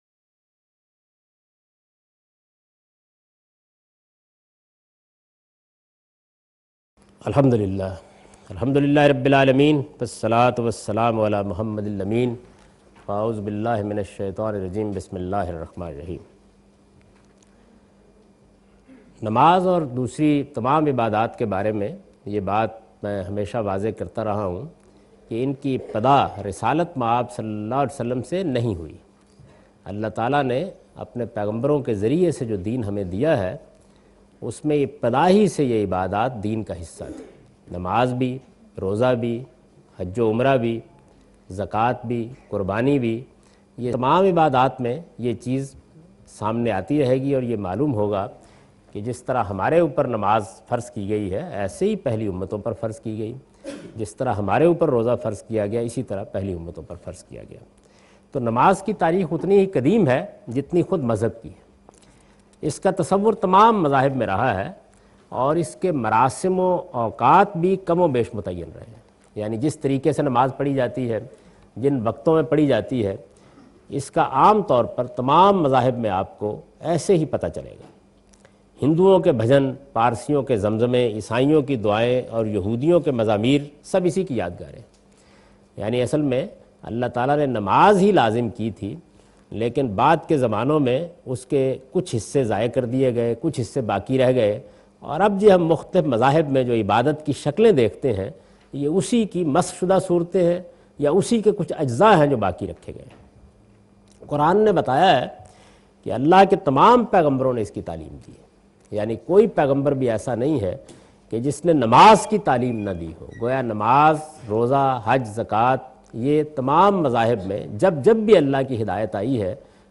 A comprehensive course on Islam, wherein Javed Ahmad Ghamidi teaches his book ‘Meezan’.
In this lecture series he teaches 'The shari'ah of worship rituals'. In this sitting he shed some light on history of prayer (Nimaz).